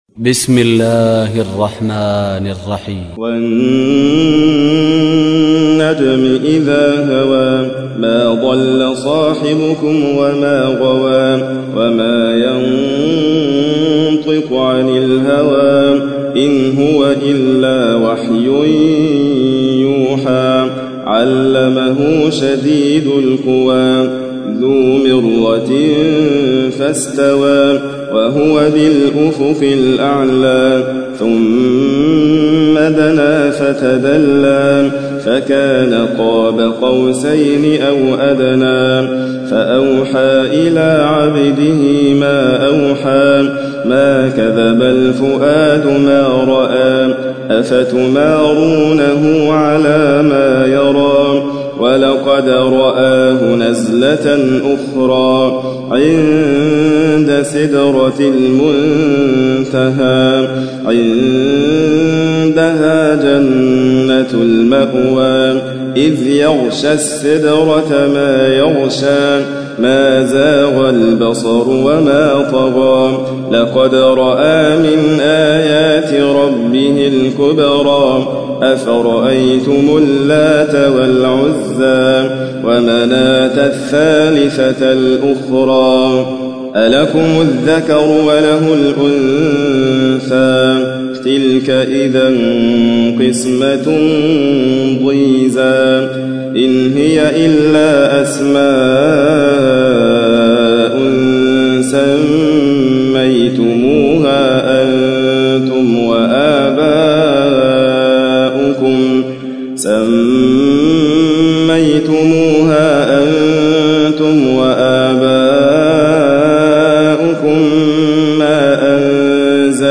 تحميل : 53. سورة النجم / القارئ حاتم فريد الواعر / القرآن الكريم / موقع يا حسين